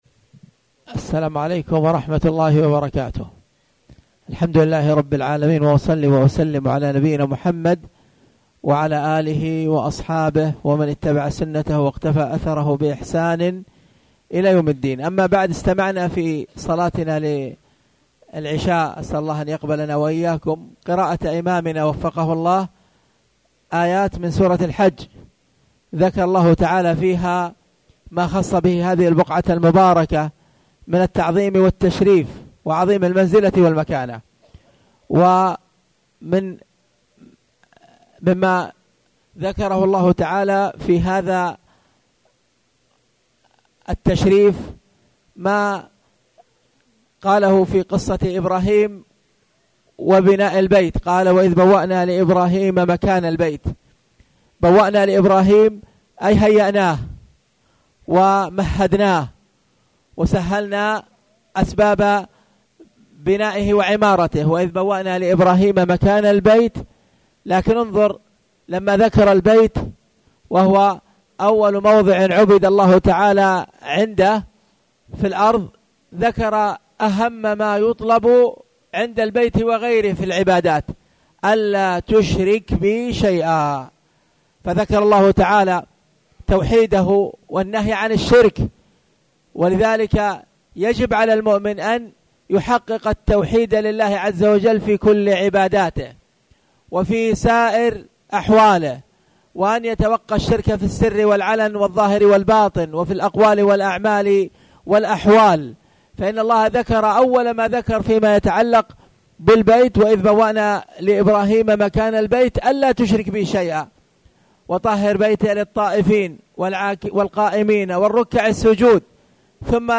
تاريخ النشر ٦ ذو الحجة ١٤٣٨ هـ المكان: المسجد الحرام الشيخ
6dhw-alhjh-mhzwrat-alihram-drs-bad-alashaa.mp3